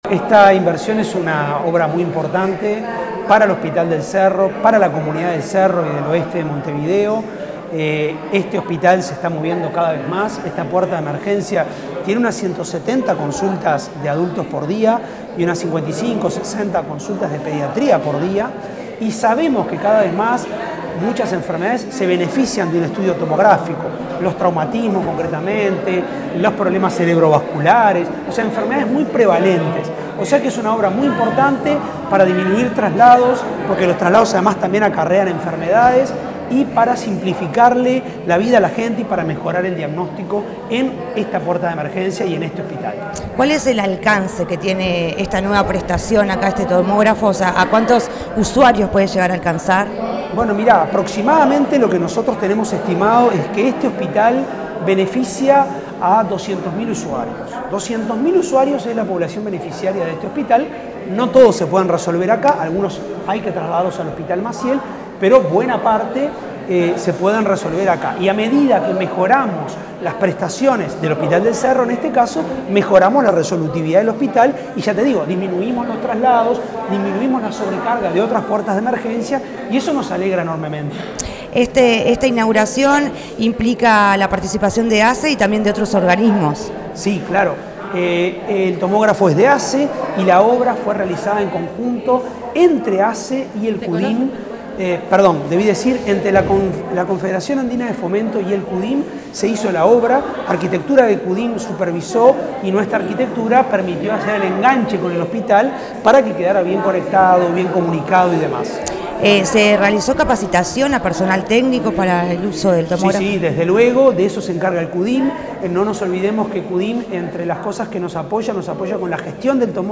Declaraciones del presidente de ASSE, Álvaro Danza
En el marco de la inauguración de un tomógrafo en el Hospital del Cerro, el presidente de la Administración de los Servicios de Salud del Estado (ASSE